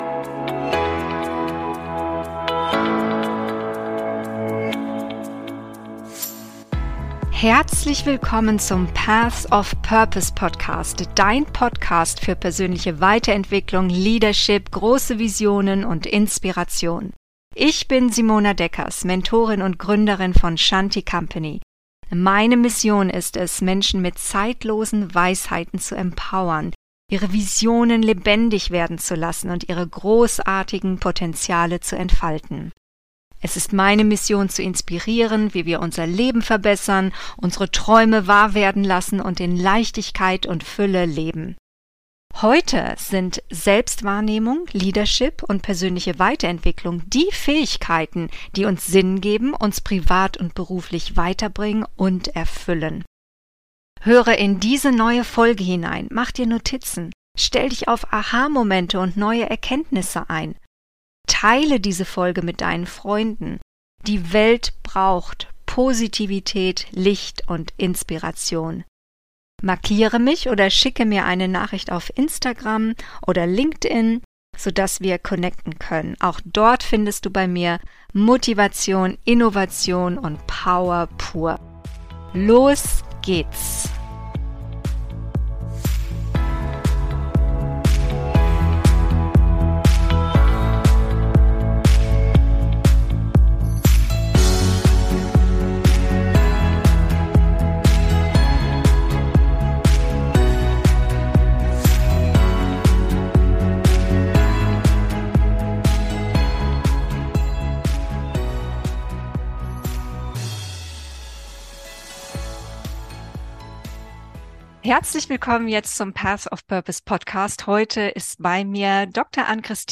Faktor Soziale Herkunft - Wie entscheidend ist unsere Herkunft? - Interview